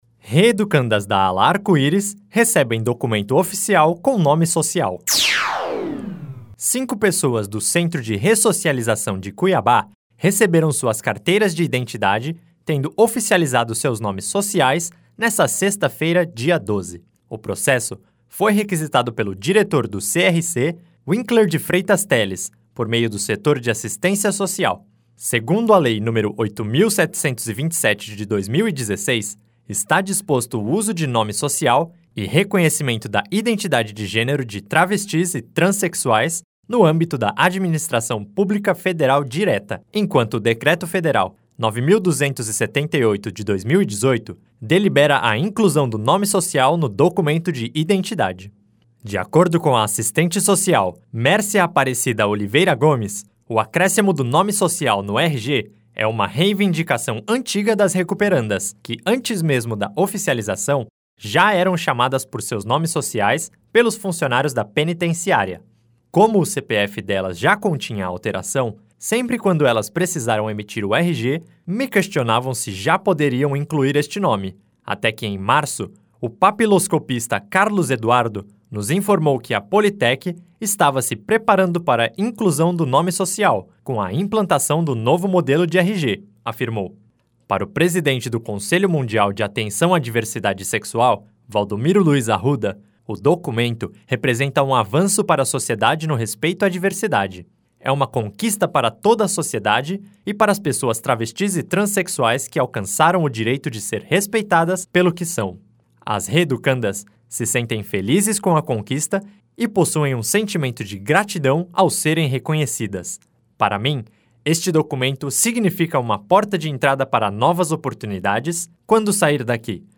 Boletins de MT 17 jun, 2019